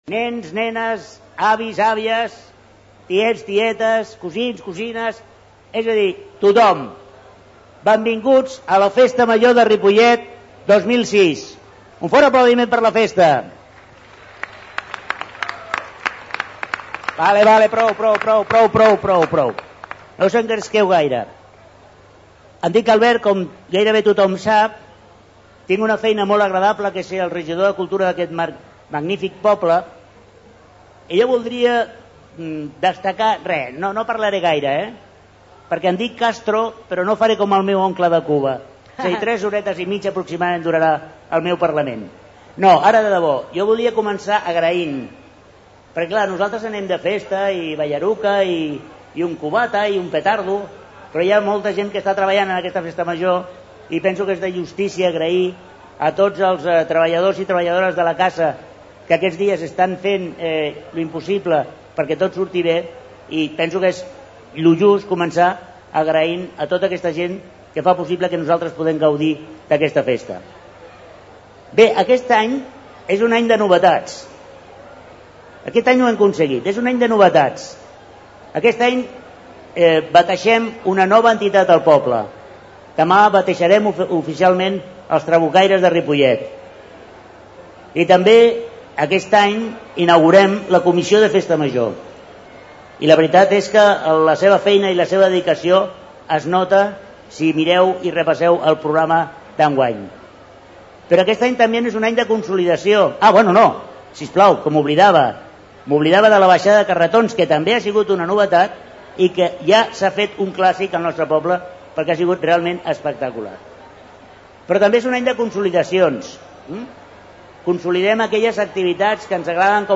Prego Festa Major2006(Part1).mp3